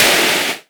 edm-clap-21.wav